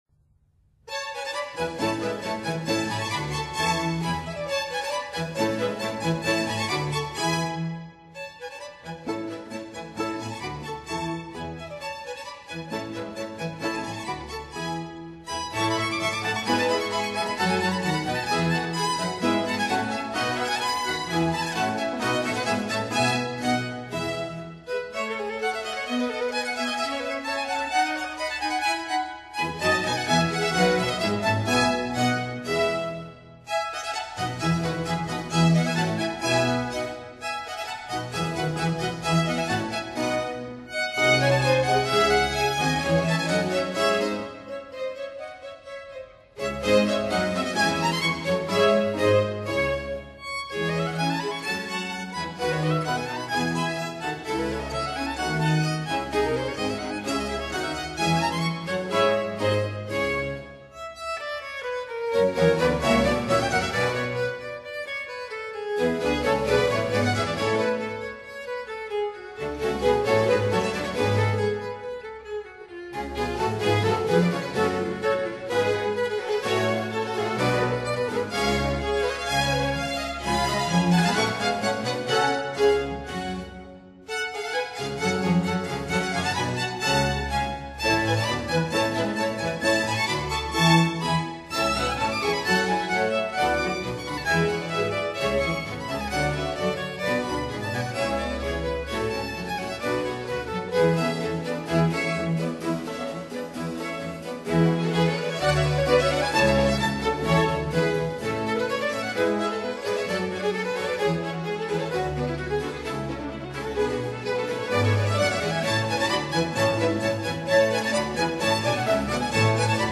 Allegro    [0:02:25.50]